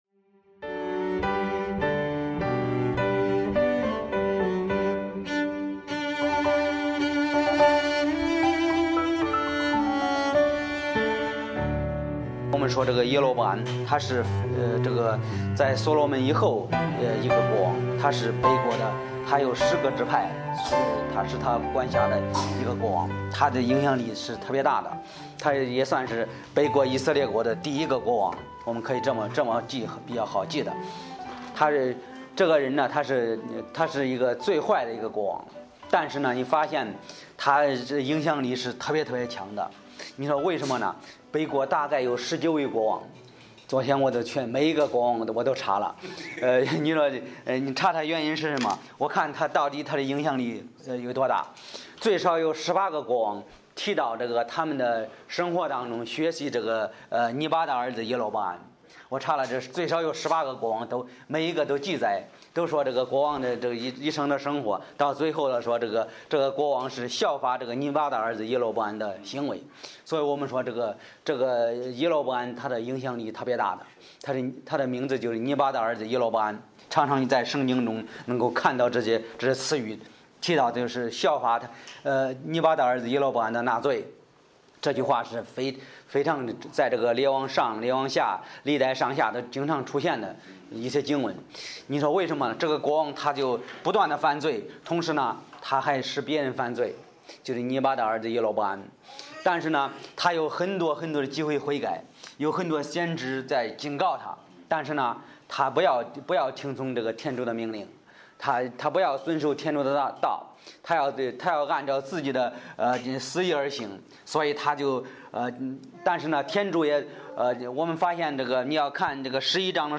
Bible Text: 列王纪上12：26-33 | 讲道者